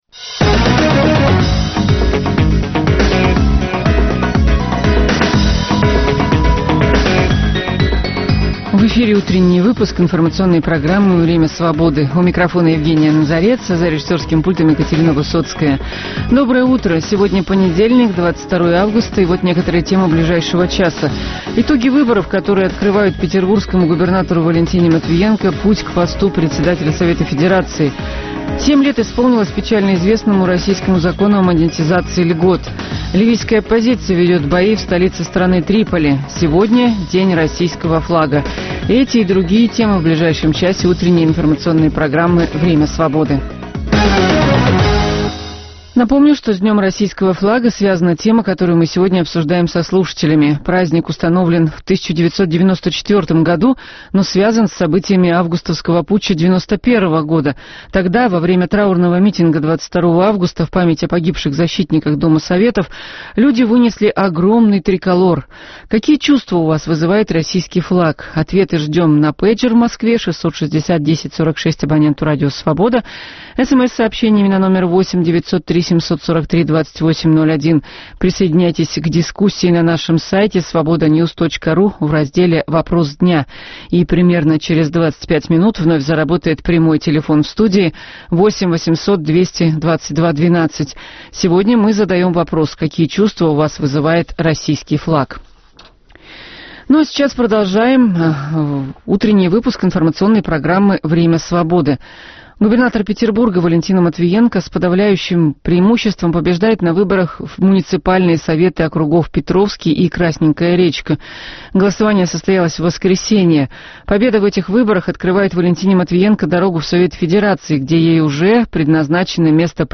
Перспективы и подробности первых событий дня наступившего, дискуссии с экспертами на актуальные темы, обсуждение вопроса дня со слушателями в прямом эфире.